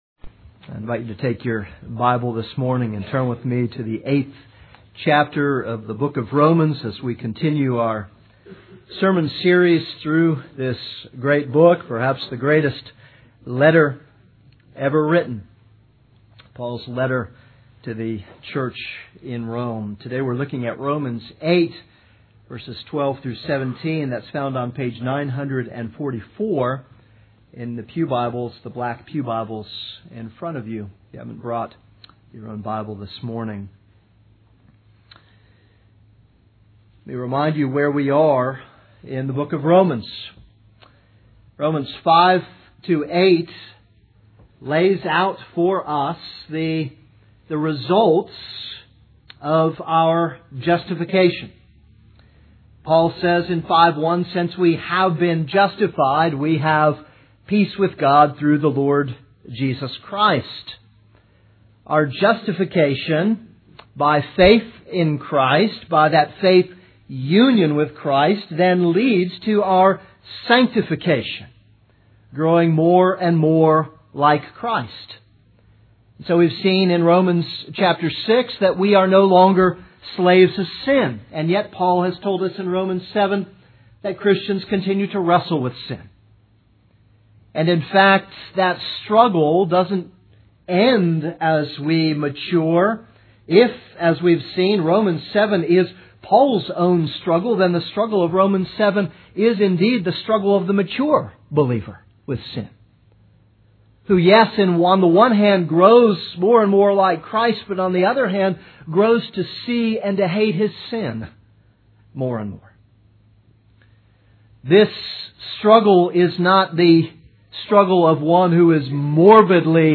This is a sermon on Romans 8:12-17.